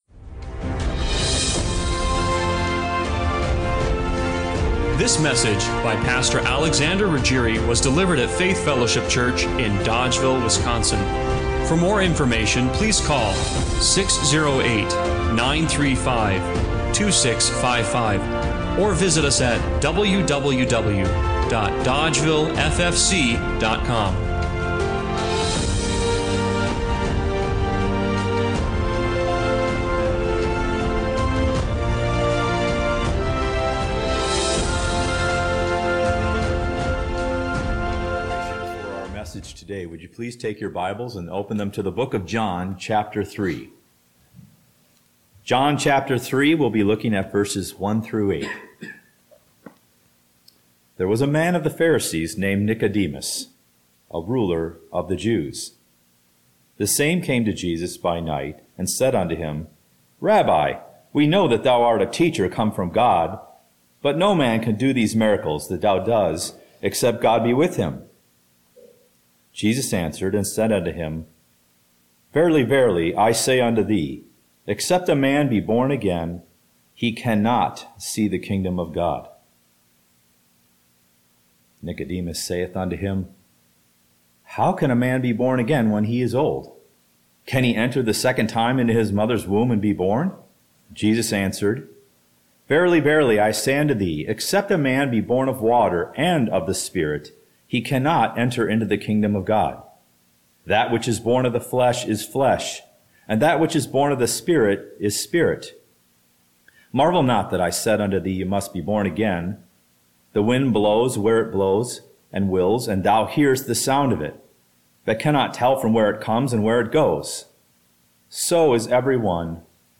John 3:1-8 Service Type: Sunday Morning Worship The wind of the Spirit is blowing—are you setting your sails